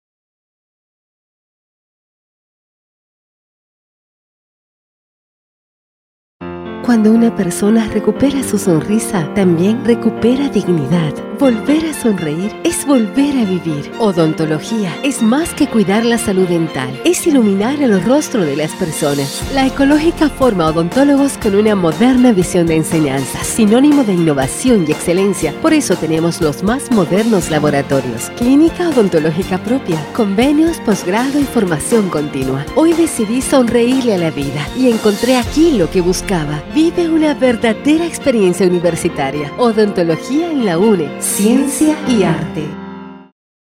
Warm, pleasant, deeply emotive, smooth and comfortable voice. Confident, energetic, professional, Corporate and Institutional.
Sprechprobe: Industrie (Muttersprache):